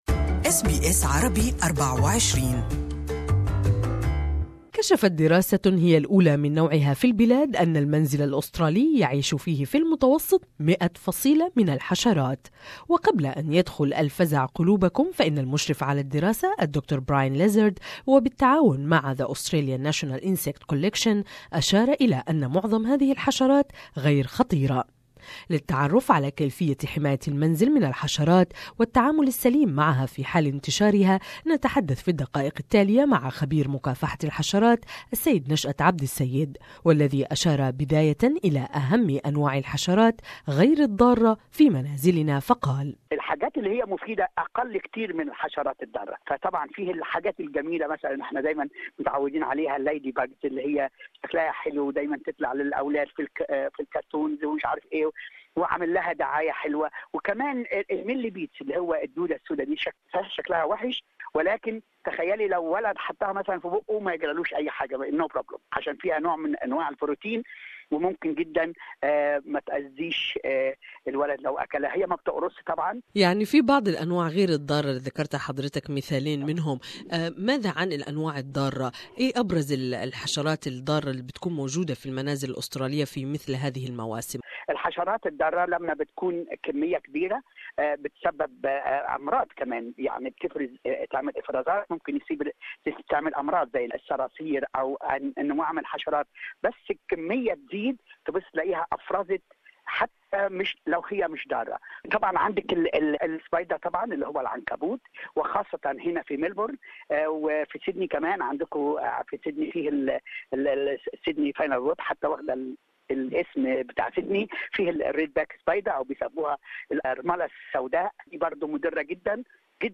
a new study has revealed Australians are sharing their homes with at least 100 types of insects More in this interview